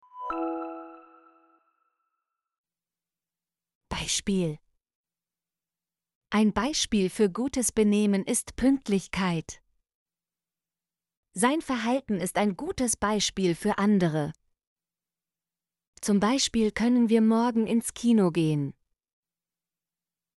beispiel - Example Sentences & Pronunciation, German Frequency List